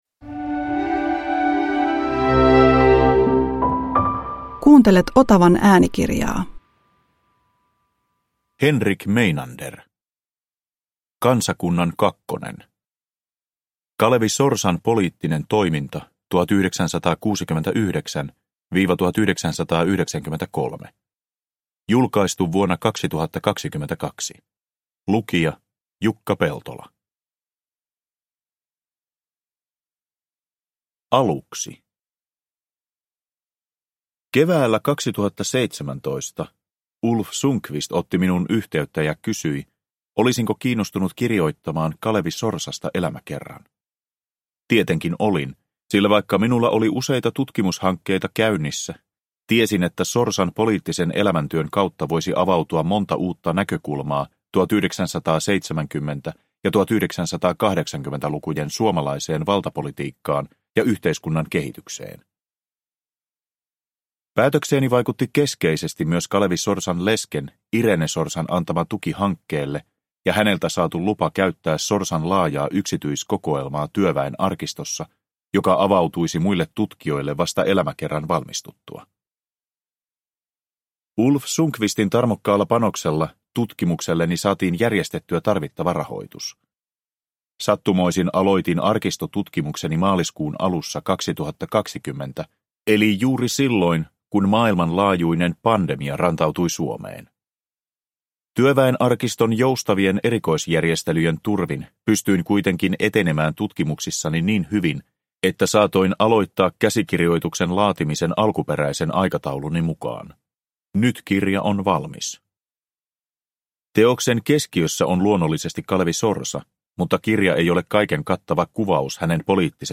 Kansakunnan kakkonen – Ljudbok – Laddas ner
Uppläsare: Jukka Peltola